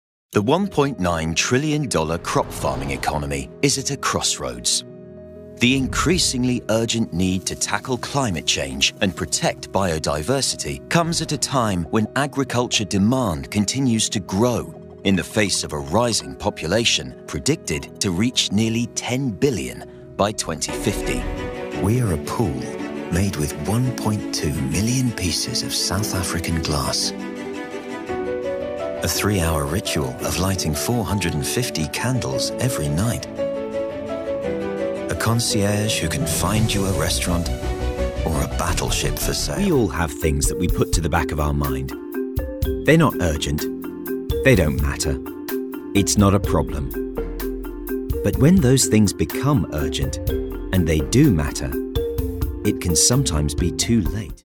Engels (Brits)
Commercieel, Diep, Veelzijdig, Warm, Zakelijk
Corporate